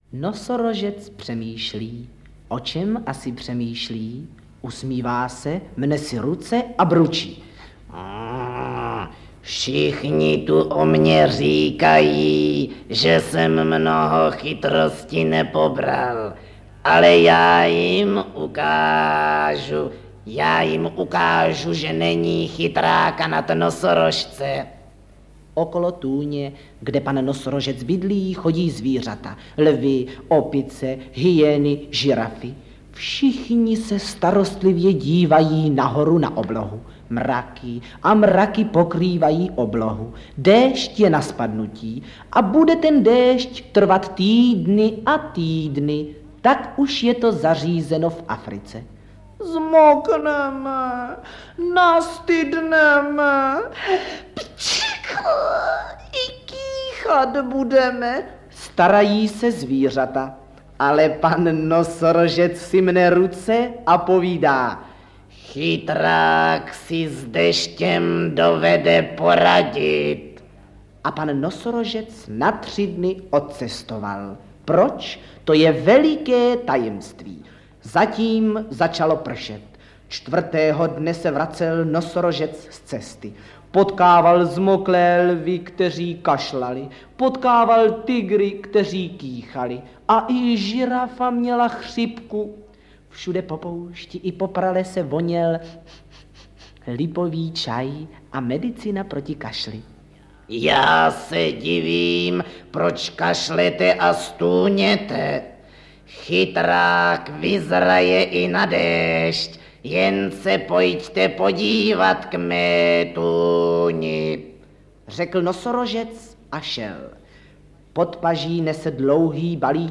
O smutném dědečkovi Halouškovi: aneb Strýček Jedlička vypravuje pohádky, zpívá a imituje
děti a mládež / předškoláci / pohádky a legendy
AudioKniha ke stažení, 18 x mp3, délka 1 hod. 16 min., velikost 68,7 MB, česky